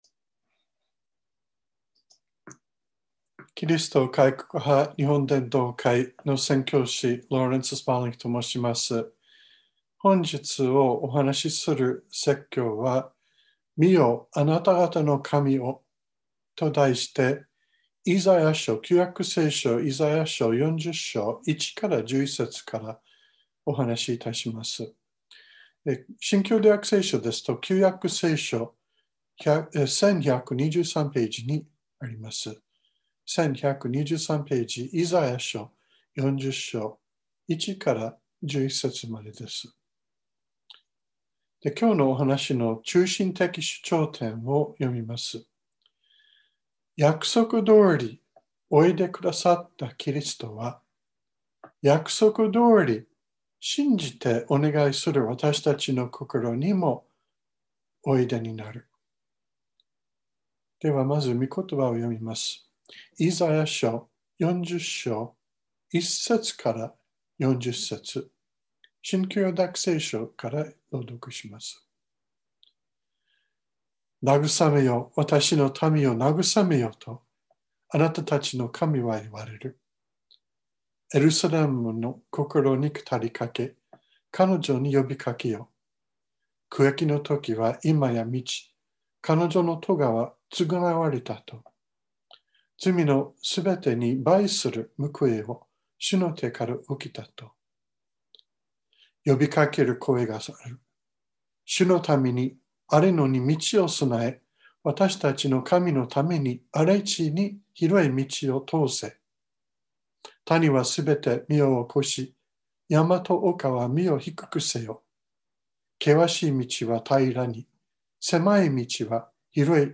2021年03月28日朝の礼拝「見よ、あなたがたの神を」川越教会
説教アーカイブ。
音声ファイル 礼拝説教を録音した音声ファイルを公開しています。